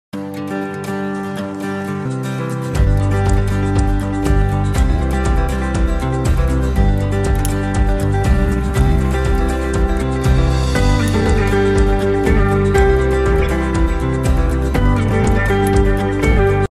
Camping hammock#camping#Outdoors sound effects free download